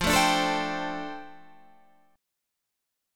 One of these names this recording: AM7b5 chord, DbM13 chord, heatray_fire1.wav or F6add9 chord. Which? F6add9 chord